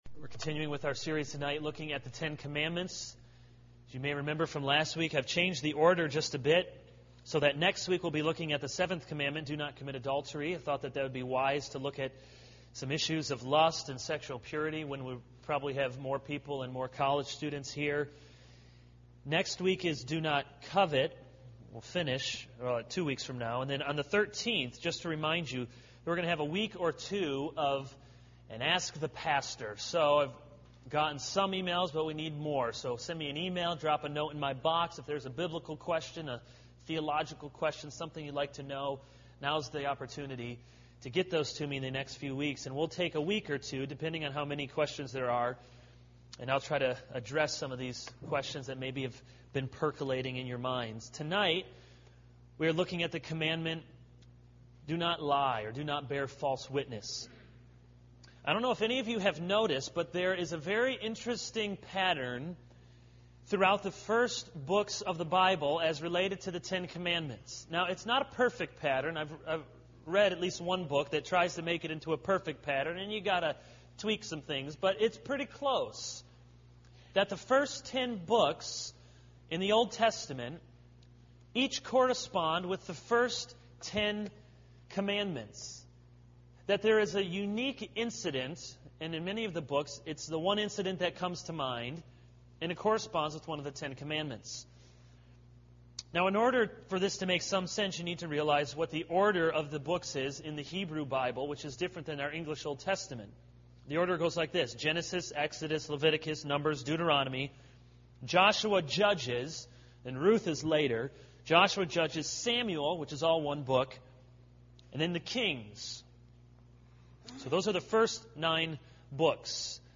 This is a sermon on Exodus 20:1-17 - Do not lie.